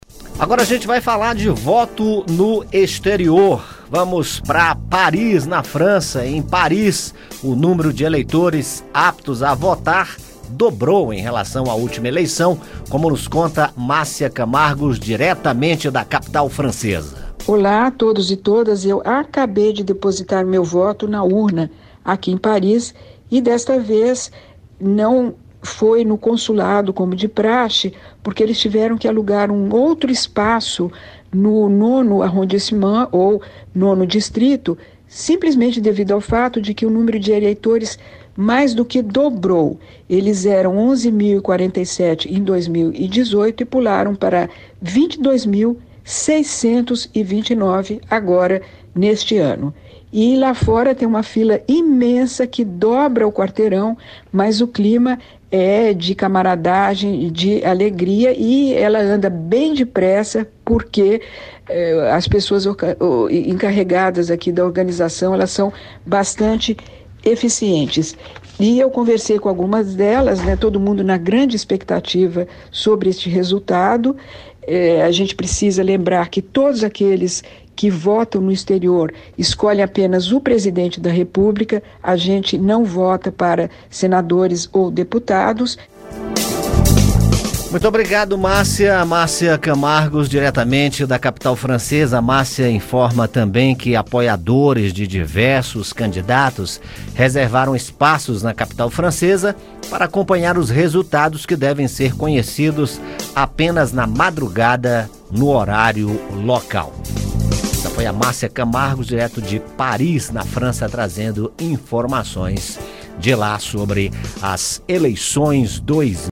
Direto de Paris
O número de brasileiros inscritos para votar mais do que dobrou esse ano, chegando a 22.600 pessoas. As filas são grandes, mas o ambiente é de tranquilidade.